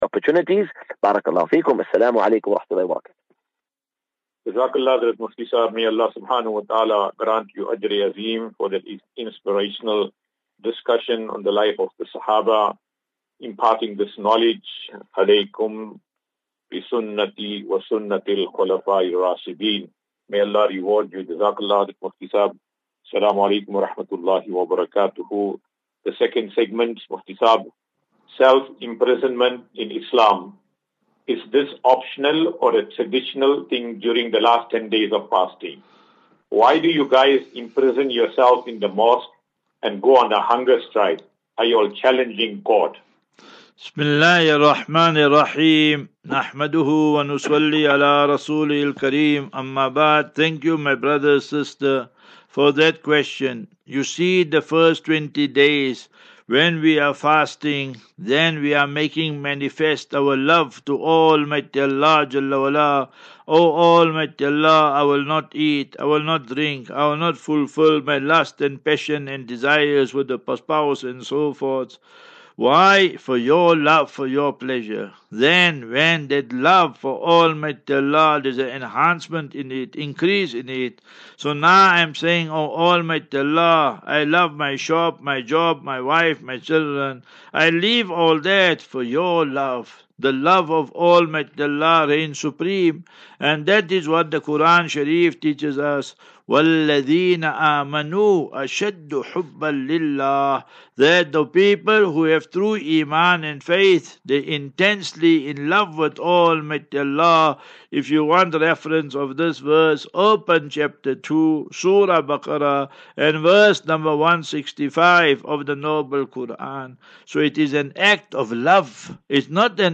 View Promo Continue Install As Safinatu Ilal Jannah Naseeha and Q and A 2 Apr 02 April 2024.